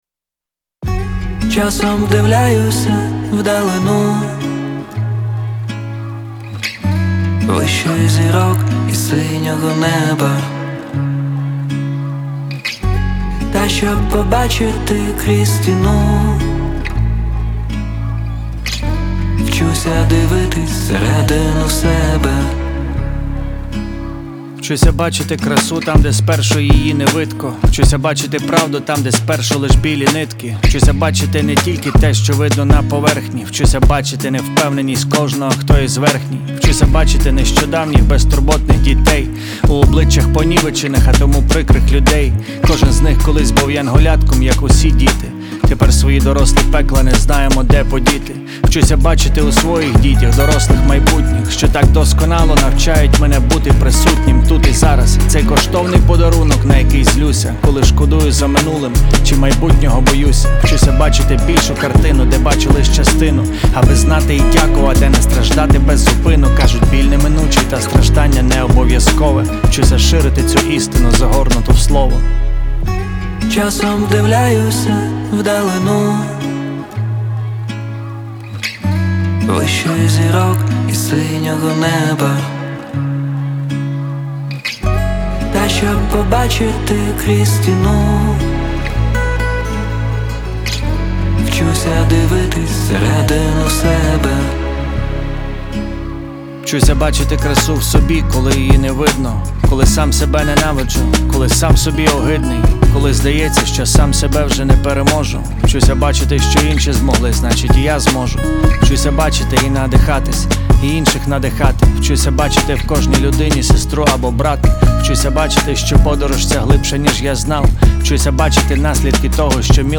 Жанр: Реп / хіп-хоп